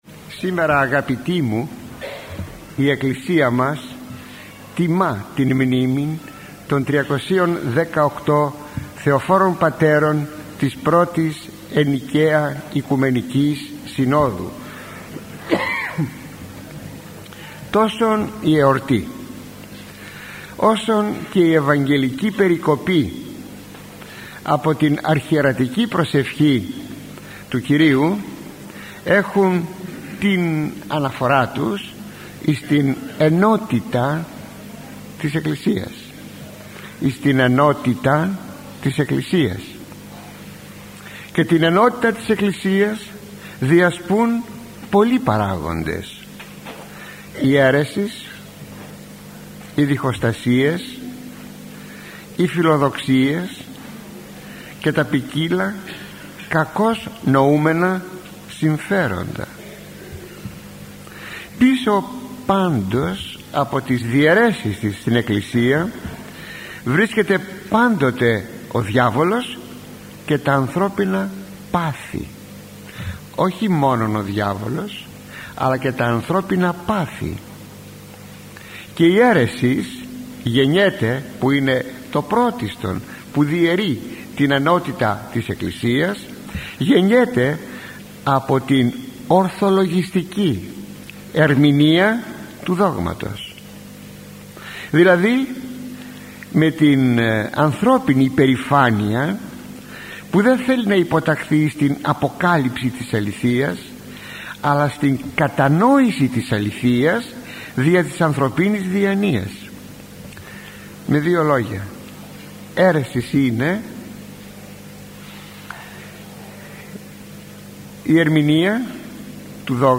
Το Αποστολικό Ανάγνωσμα της Κυριακής των Πατέρων της Α. Οικουμενικής συνόδου – Ηχογραφημένη ομιλία του Αρχιμ.